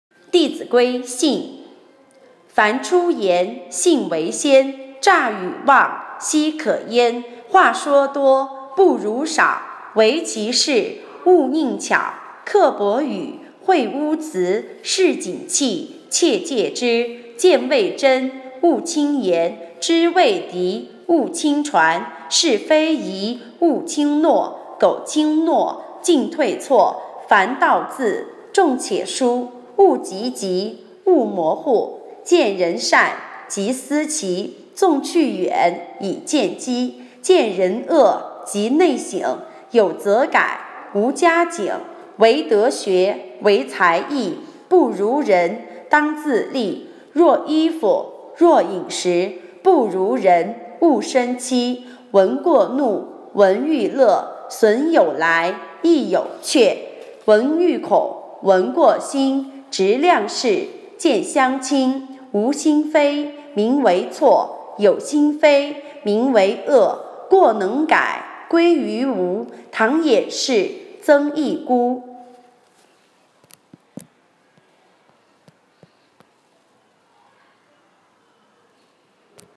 课件共享:国学朗读